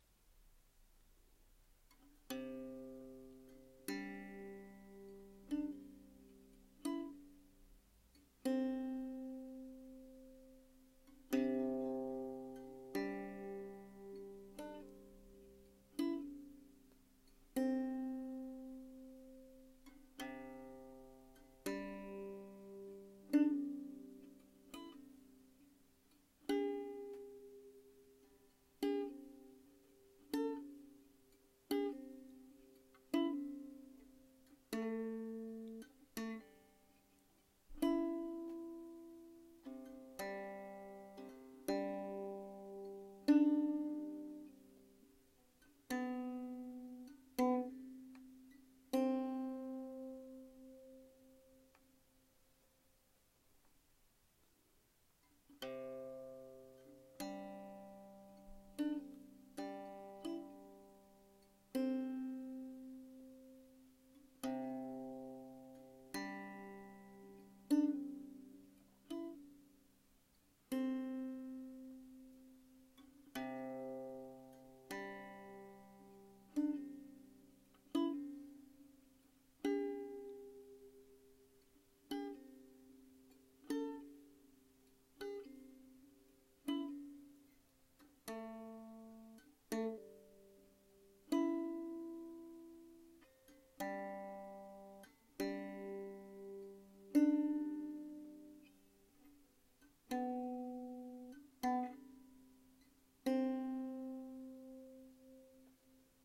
三味線
駒：象牙（オリジナル）